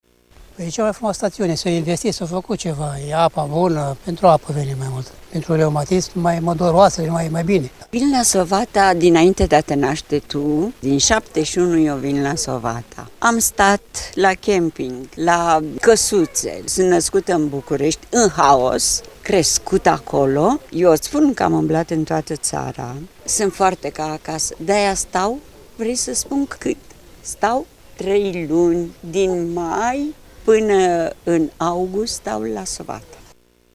Sovata-voxuri-2.mp3